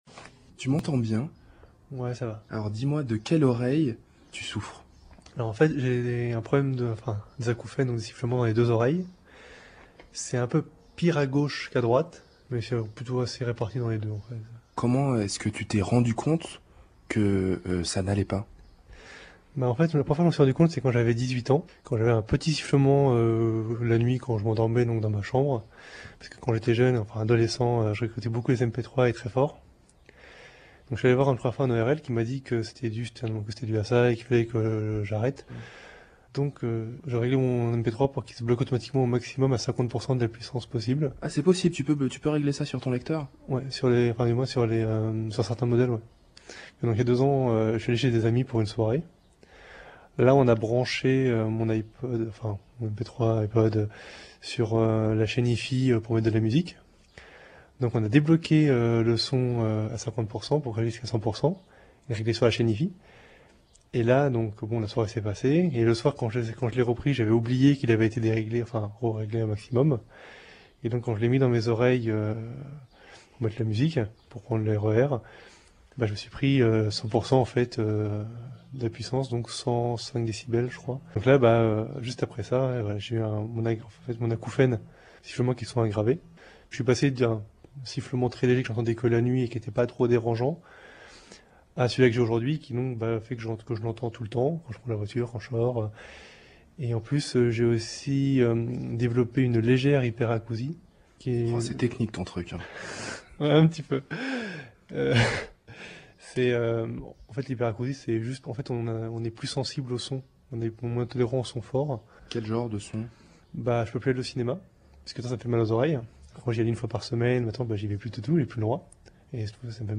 Voici un témoignage entendu à la radio l’autre jour.